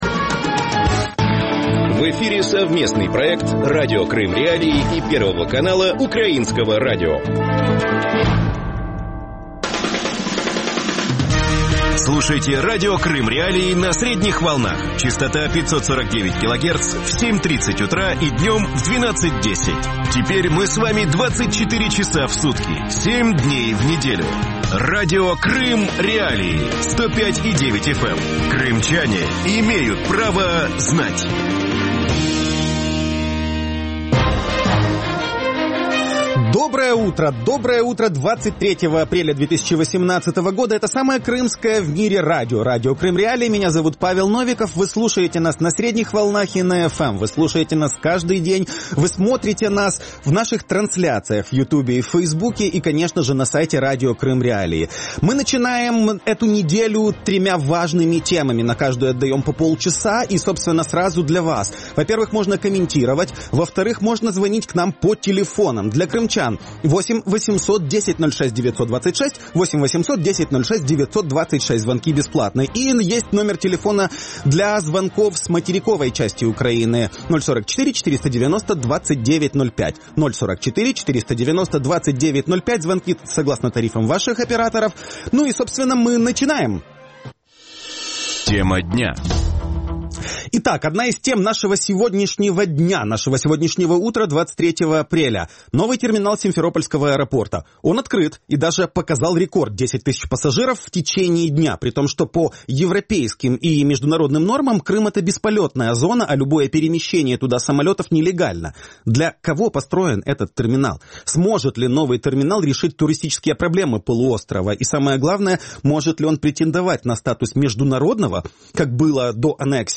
Гости эфира: Александр Лиев, экс-министр курортов и туризма Автономной Республики Крыма; Александр Кава, эксперт в сфере транспорта, бывший замминистра инфраструктуры Украины;
украинский авиаэксперт.